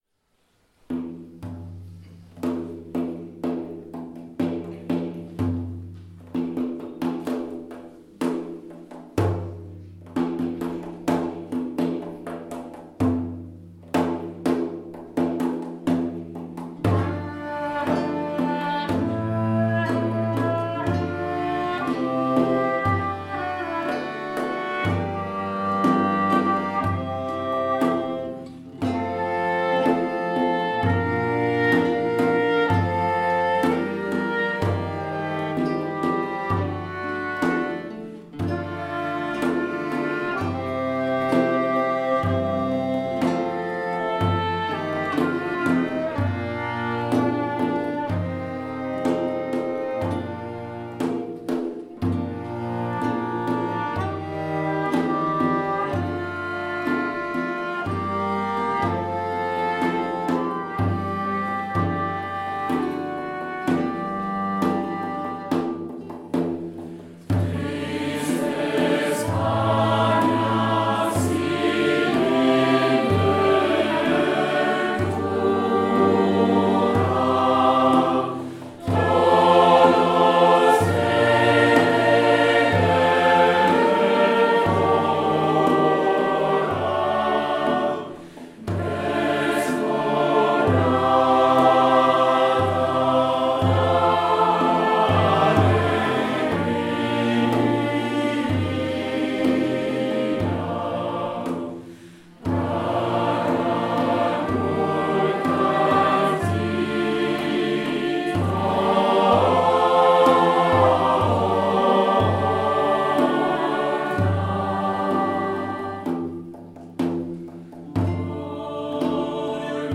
Concert Temple Protestant Montigny le Bretonneux 8 avril 2016
4 - Anonyme Ay luna que reluces (instrumental)